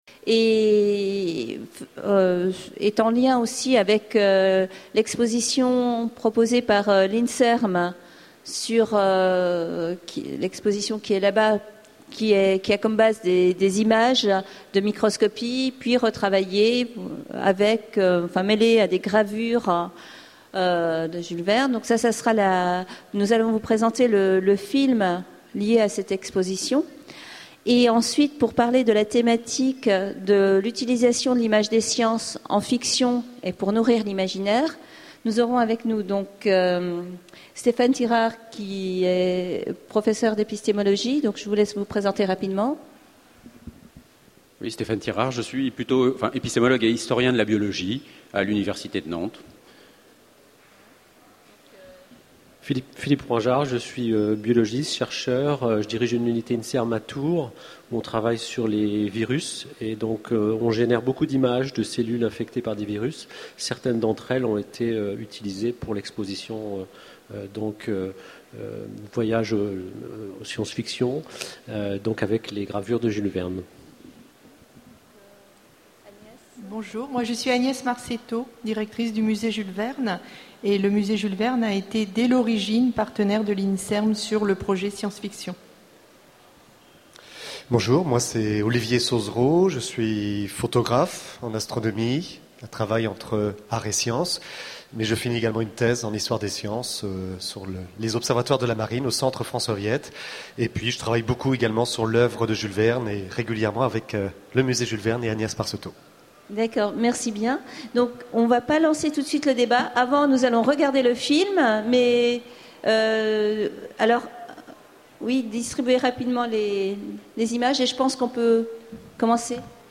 Utopiales 2011 : Conférence Quand l'image de la science conduit à la Sf